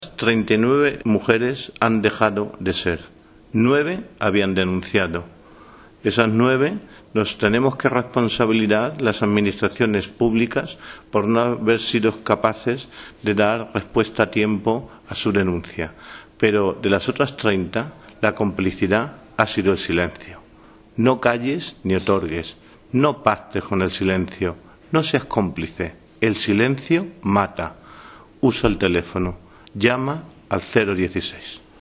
cortealcaldeviolenciagenero-1.mp3